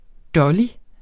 Udtale [ ˈdʌli ]